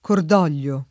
kord0l’l’o] s. m.; pl. ‑gli — una pn. preval. con -o- aperto, continuaz. regolare dell’-o- breve del lat. cordolium «il cuore che duole»; una pn. di minoranza con -o- chiuso, suggerita forse da orgoglio, rigoglio e simili